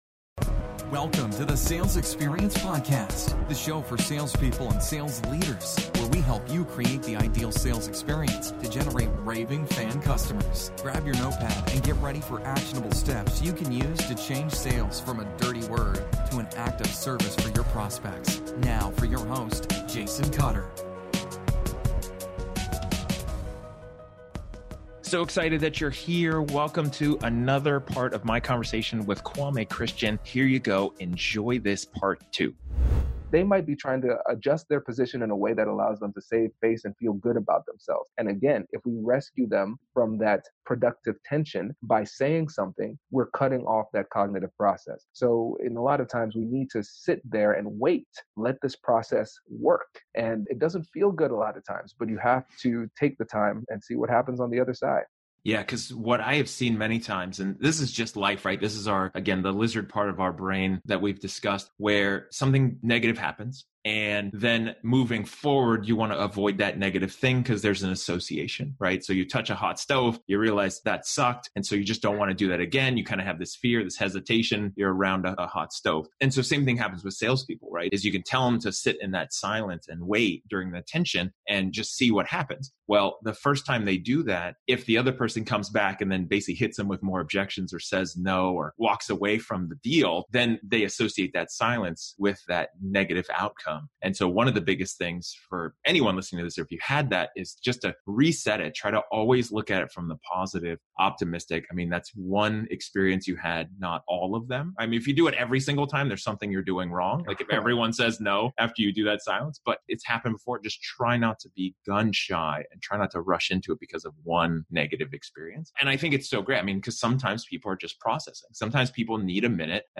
Part 2 of my conversation